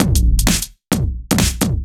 OTG_Kit 1_HeavySwing_130-A.wav